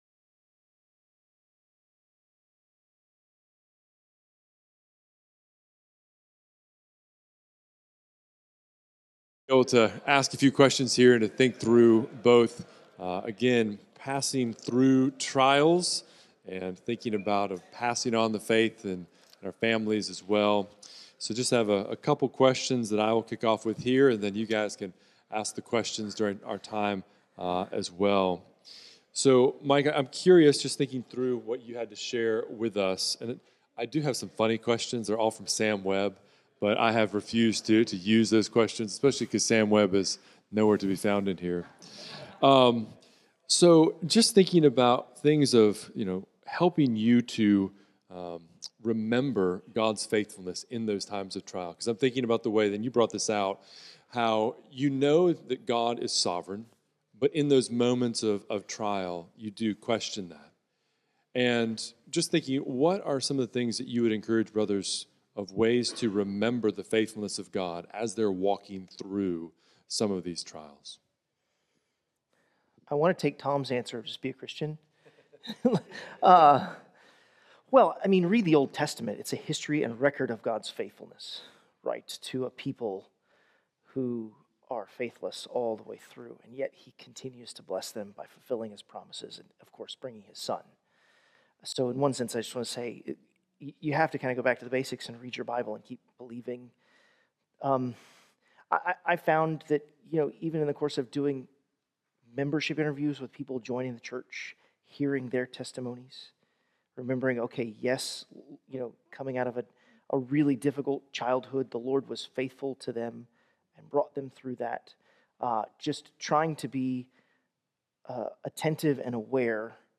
Pastoring & Trials Panel